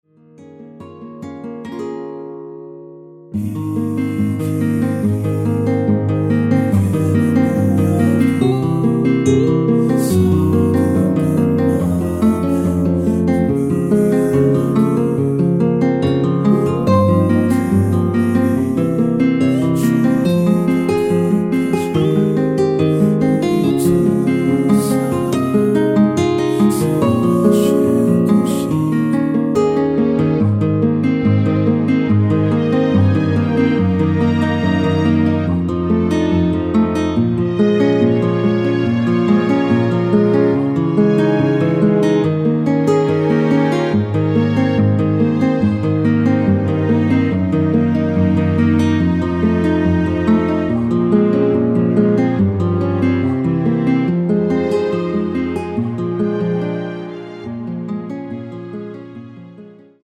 원키에서(-1)내린 2절 삭제 코러스 포함된MR 입니다.
키 Ab 가수
원곡의 보컬 목소리를 MR에 약하게 넣어서 제작한 MR이며